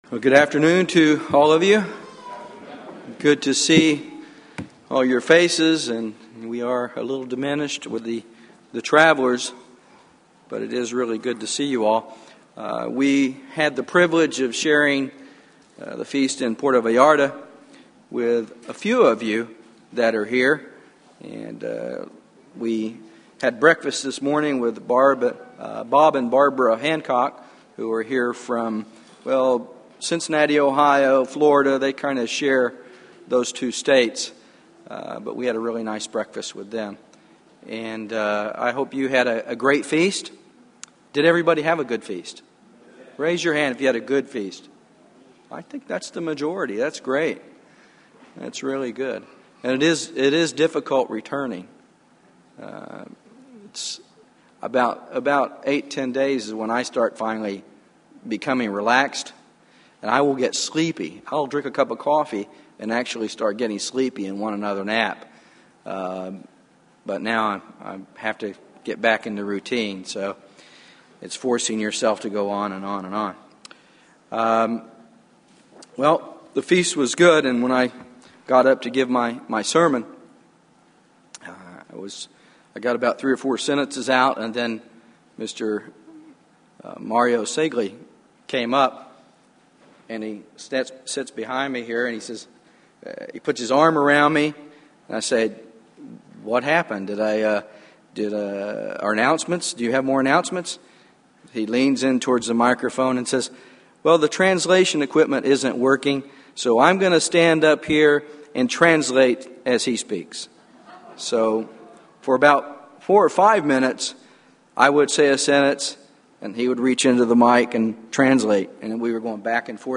Given in Dallas, TX
His Self-exaltation and my self-denial are not divided. sermon Studying the bible?